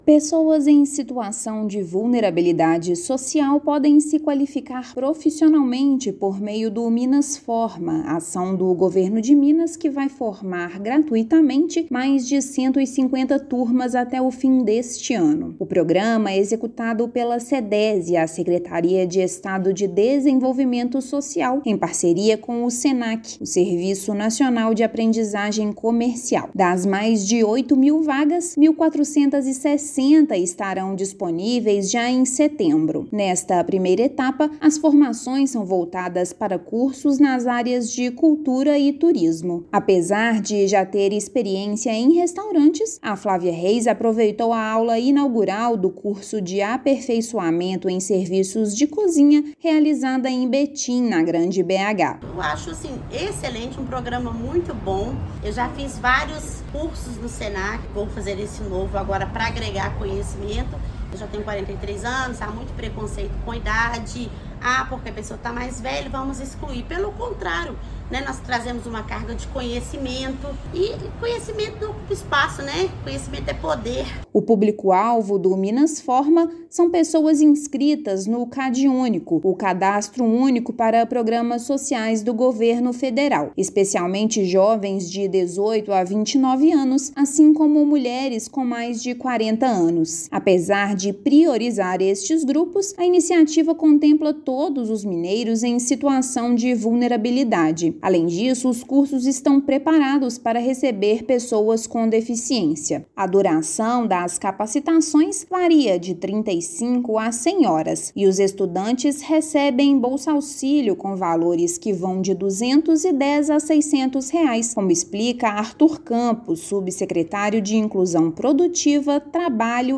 Com previsão de mais de 150 turmas para 2024, programa qualifica profissionais para setores de Turismo e Cultura. Ouça matéria de rádio.